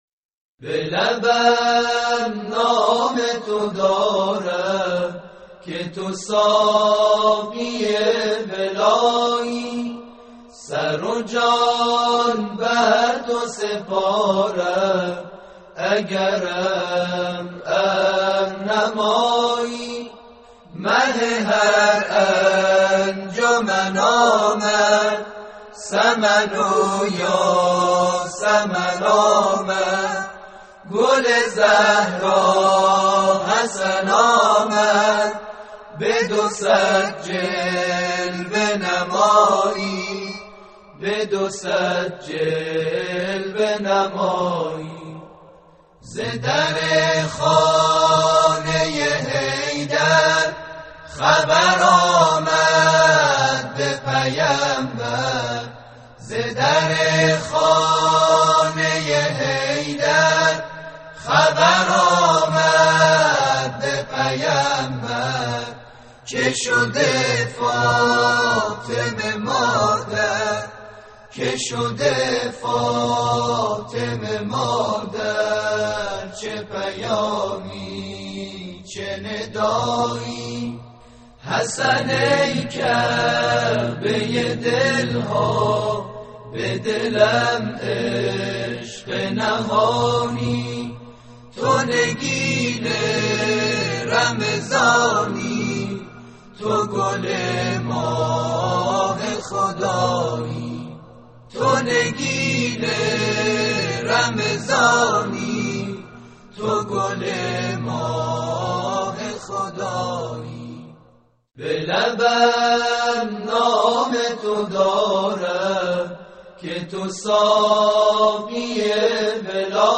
همخوانی - به لبم نام تو دارم